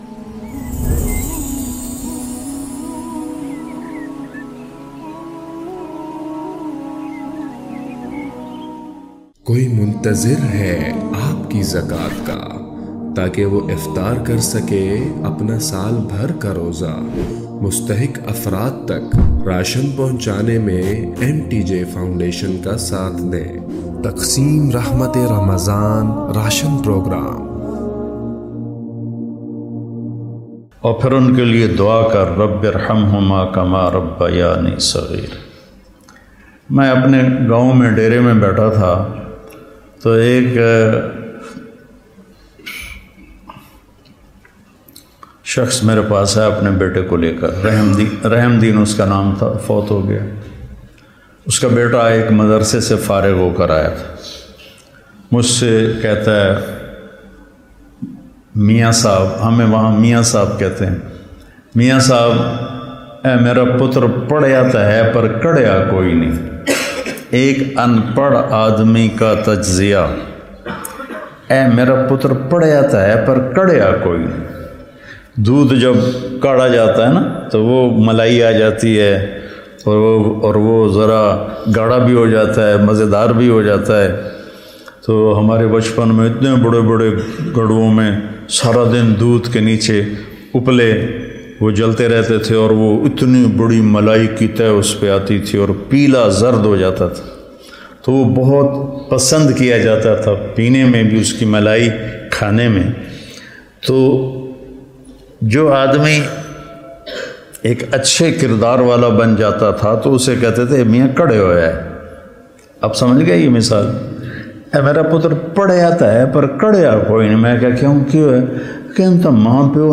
What is Paighaam (Message) of Quran? Urdu 2022 Bayan by Maulana Tariq Jameel
Maulana Tariq Jameel is regarded as one of the greatest Islamic preachers of our times.